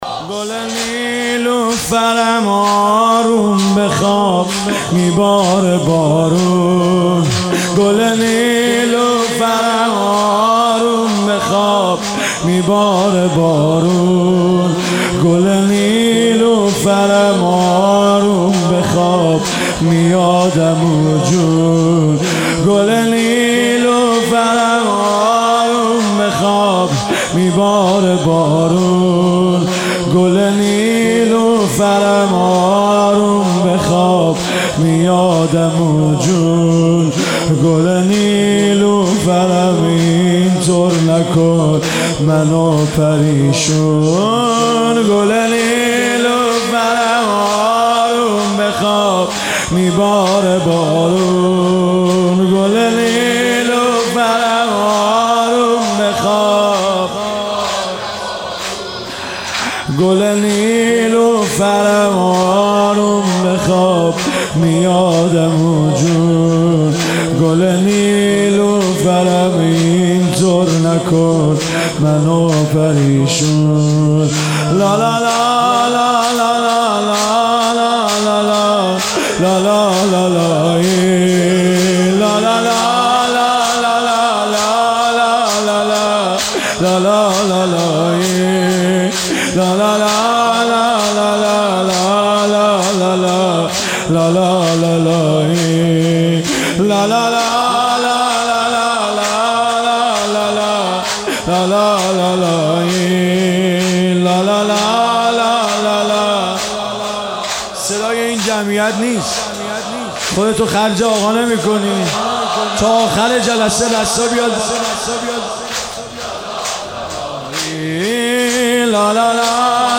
گلچین بهترین مداحی های
نوحه کیفیت بالا مداحی صوتی محرم